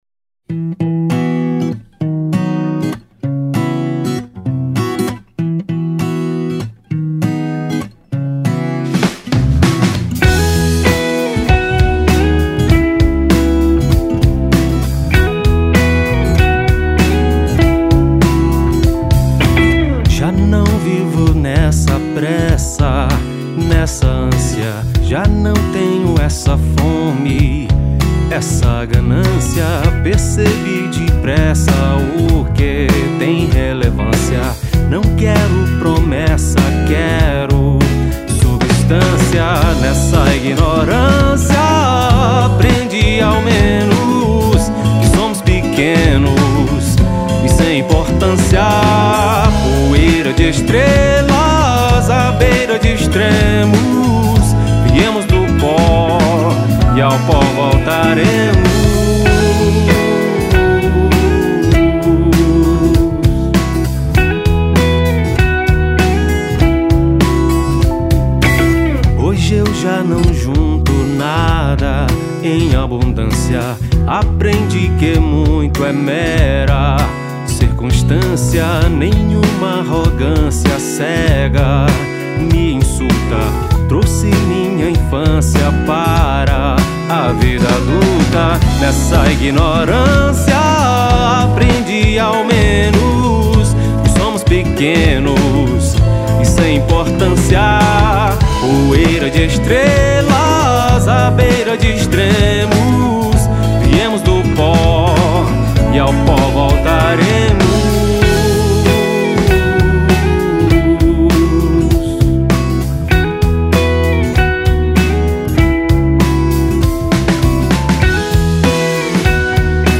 1276   04:13:00   Faixa:     Rock Nacional